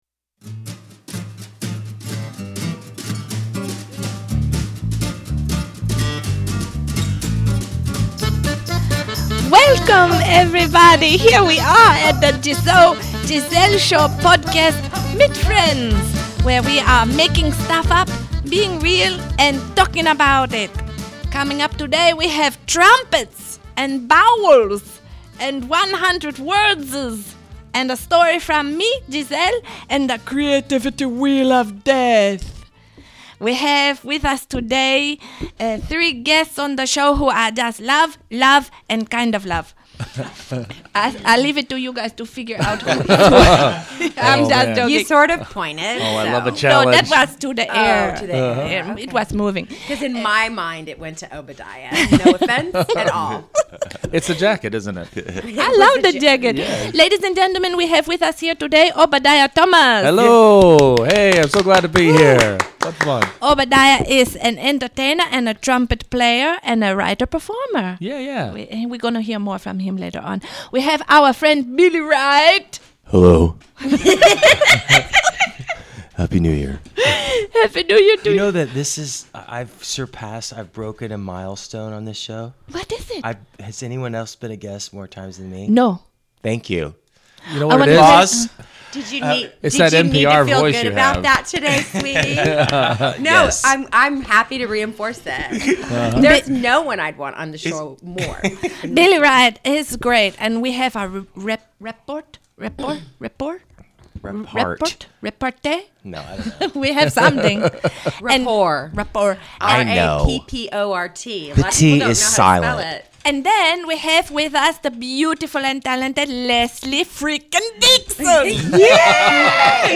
Photos from the Live Recording: